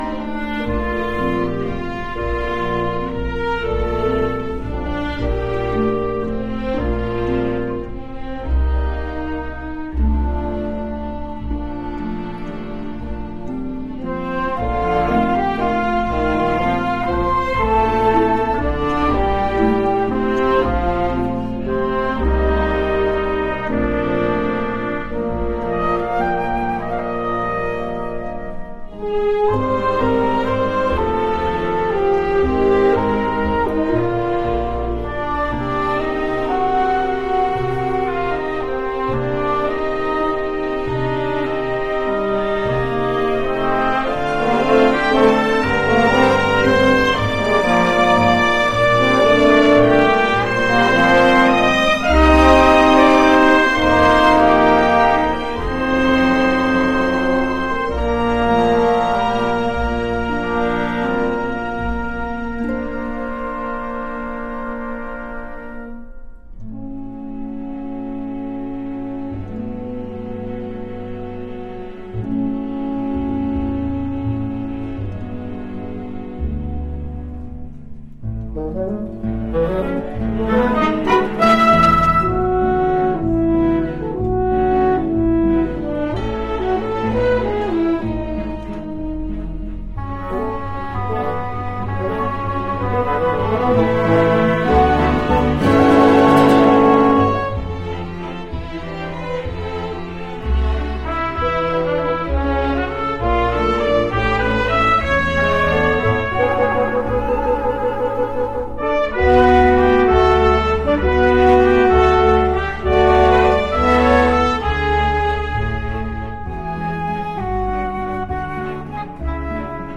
MP3 clip from this title's soundtrack